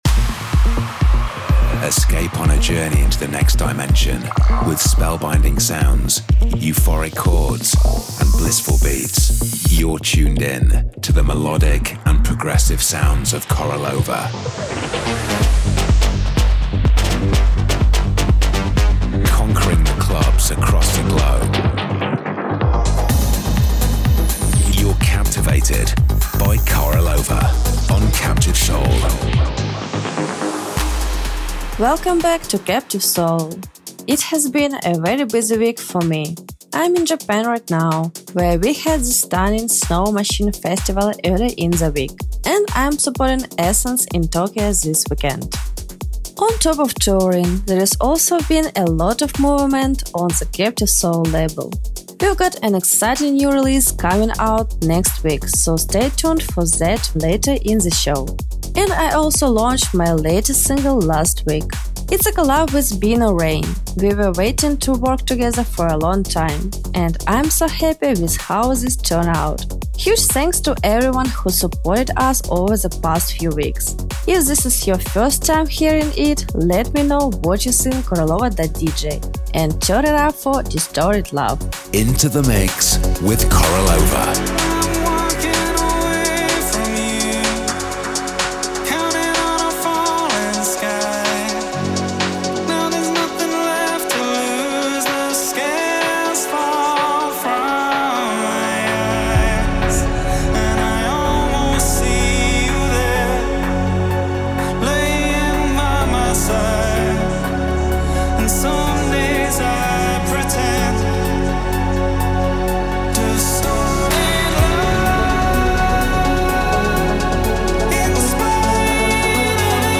The bi-weekly radio show